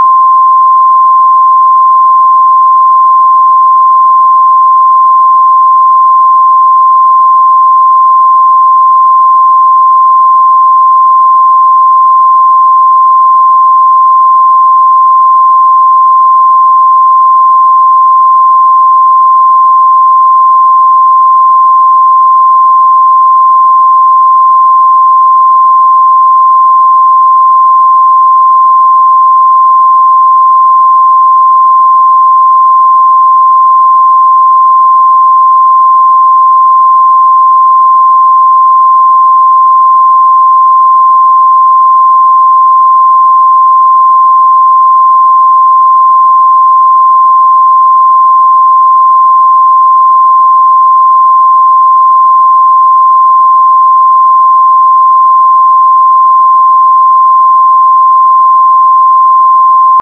Es empfiehlt sich zunächst einen Ton ohne Lautstärkeschwankungen zu verwenden, wie zum Beispiel einen Sinuston:
Sinuston 1047 Hz
Für den Sinuston von oben ergibt sich damit eine Wellenlänge von ungefähr 0,328 m.
Sinuston_1047Hz.m4a